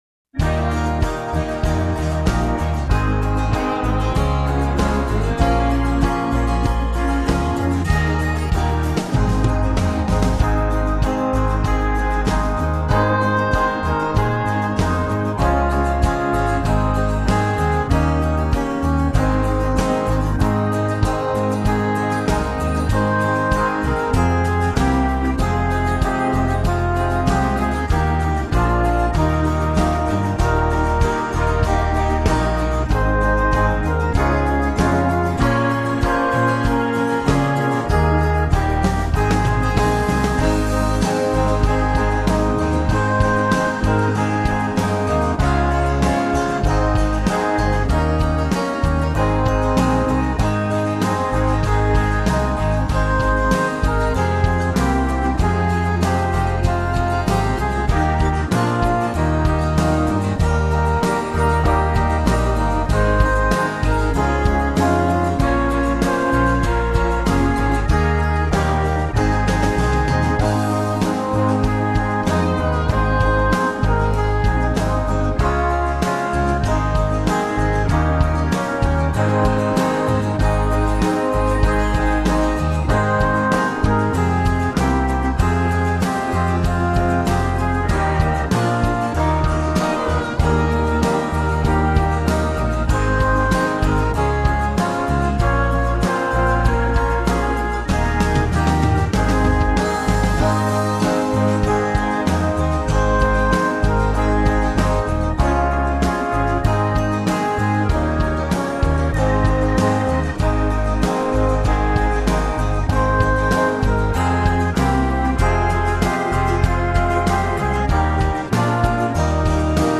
An unnecessary backing as you probably know this one: